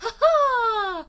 peach_yahoo3.ogg